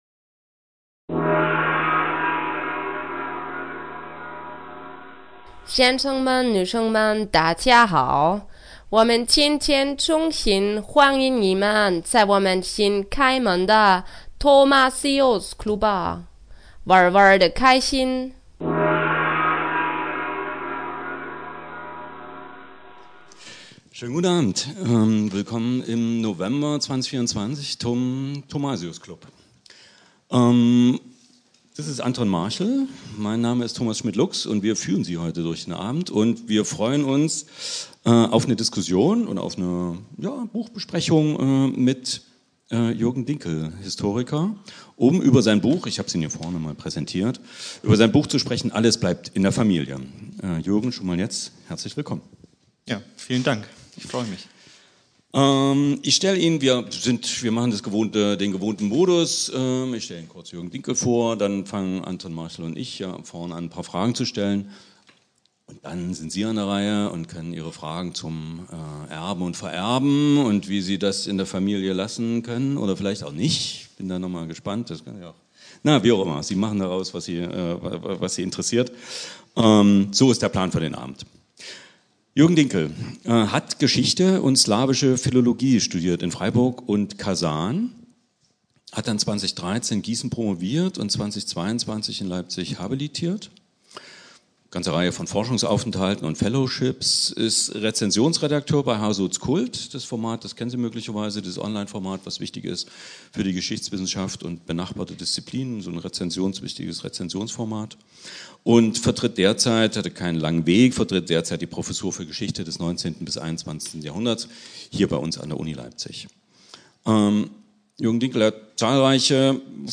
27. November 2024 (20:00 Uhr – Café Alibi)